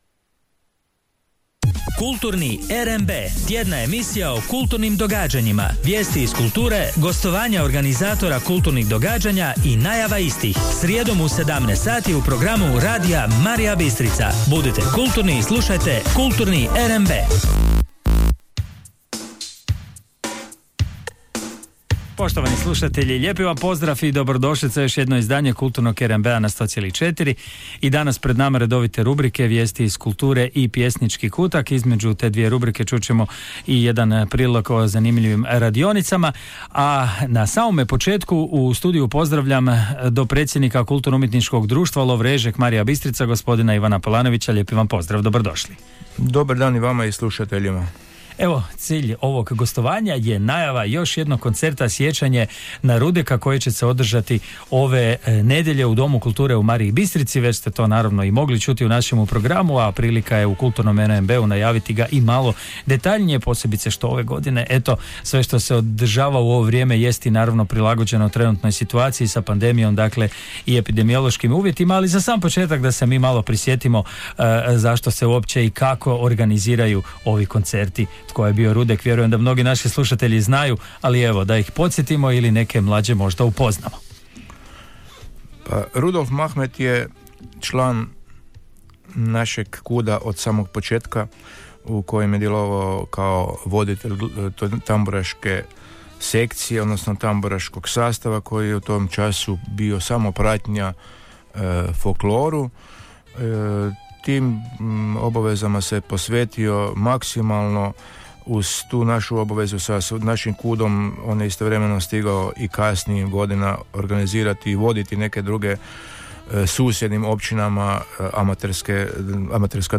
Snimka radio emisije